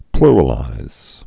(plrə-līz)